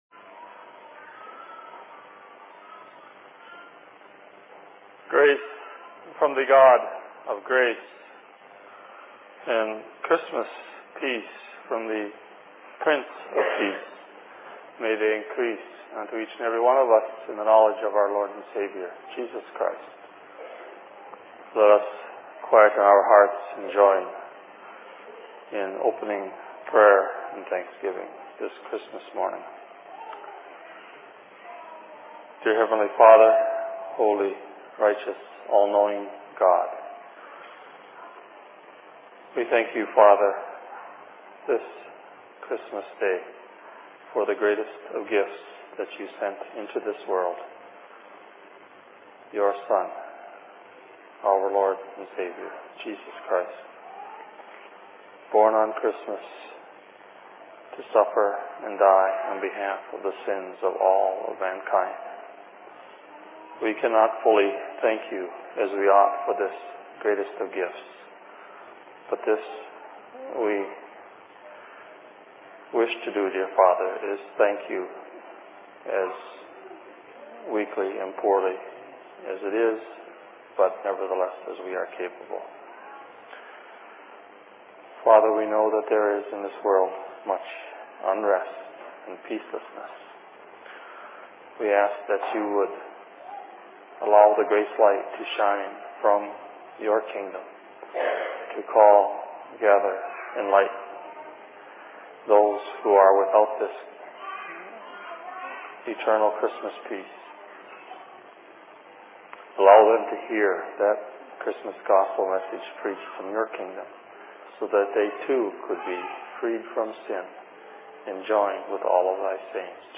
Sermon in Minneapolis 25.12.2004